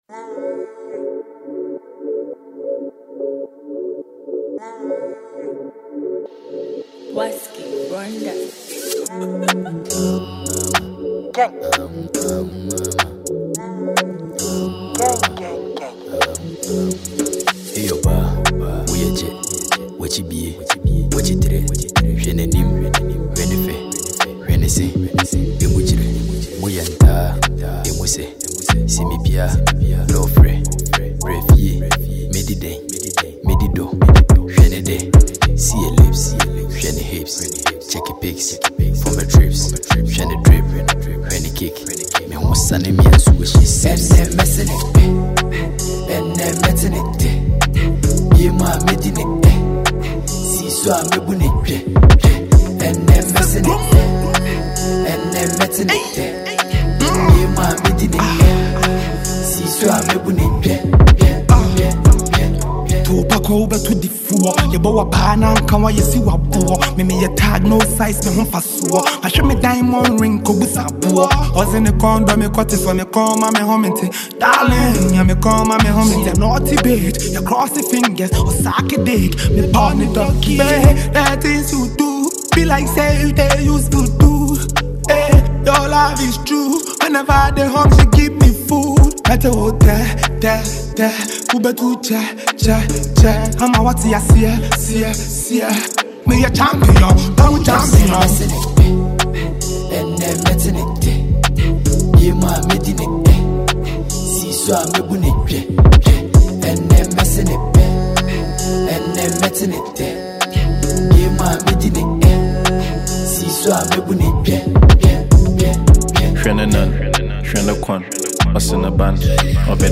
an asakaa rapper from the Gold Coast of West Africa